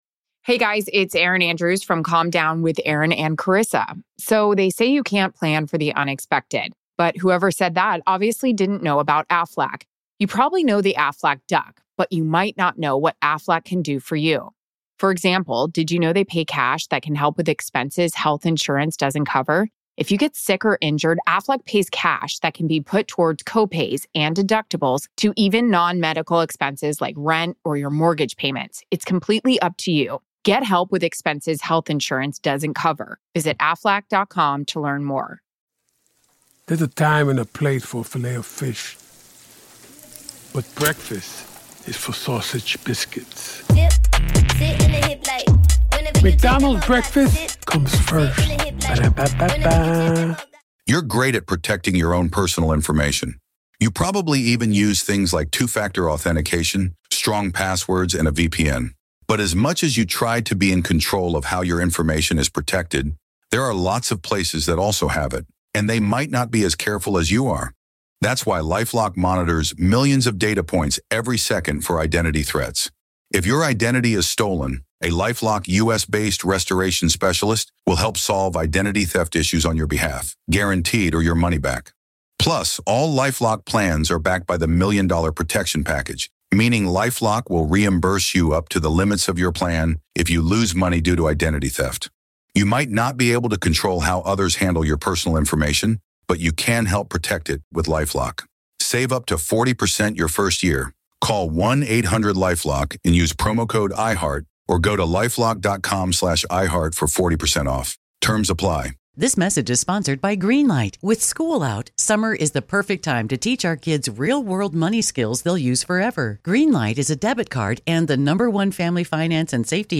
Interview Part 2